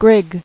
[grig]